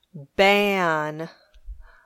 v and b Sounds
ban.mp3